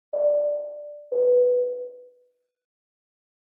Airplane Cabin Ding Sound Effect
Notification Sounds / Sound Effects
Airplane-cabin-ding-sound-effect.mp3